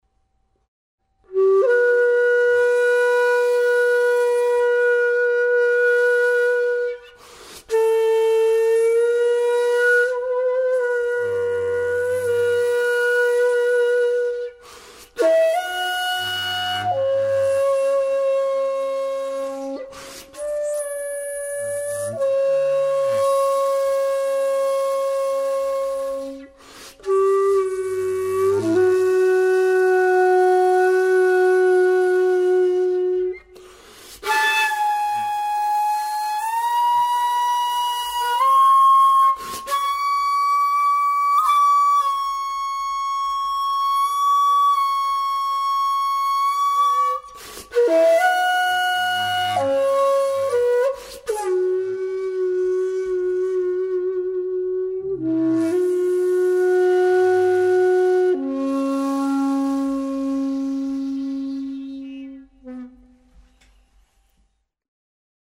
1.85 C#  This flute has a very distinct personality.
Its tone is dark, smooth, subtle and very expressive.